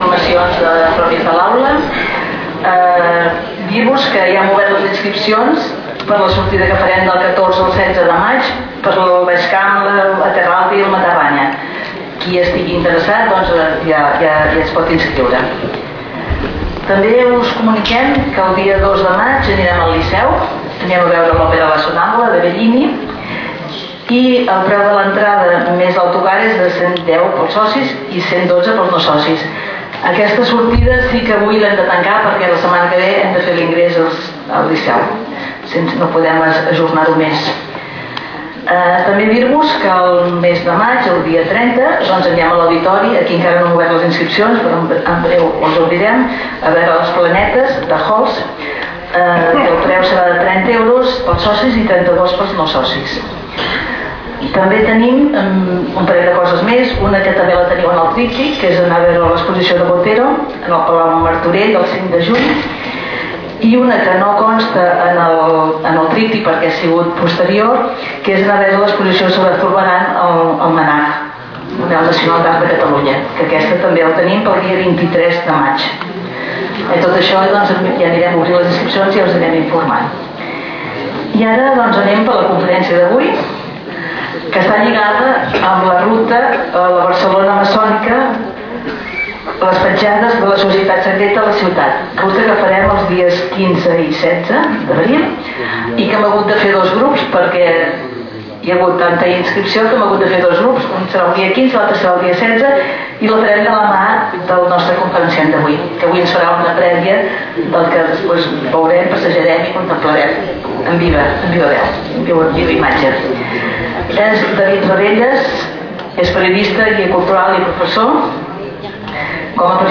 Lloc: Sala d'actes del Col.legi La Presentació
Categoria: Conferències